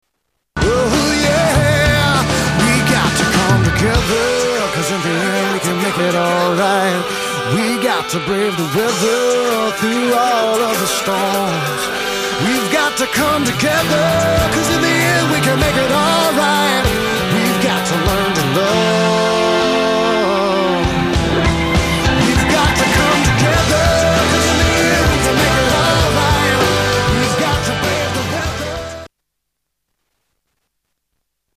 STYLE: Rock
The production quality is top rate.